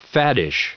Prononciation du mot faddish en anglais (fichier audio)
Prononciation du mot : faddish